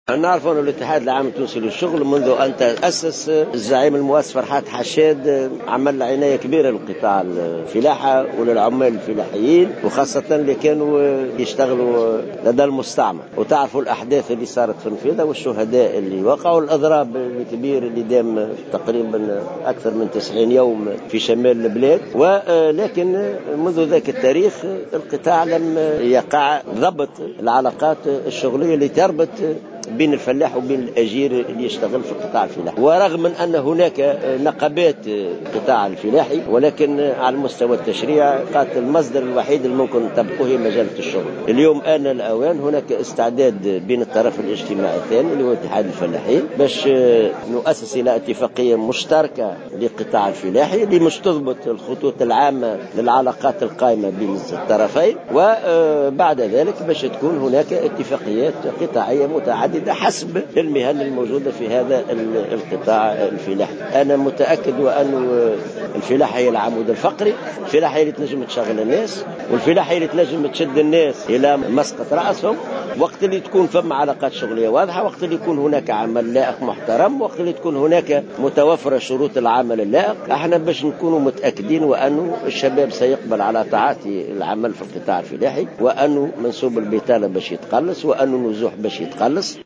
en marge d’une conférence tenue à Hammamet